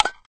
clack_can_opening.ogg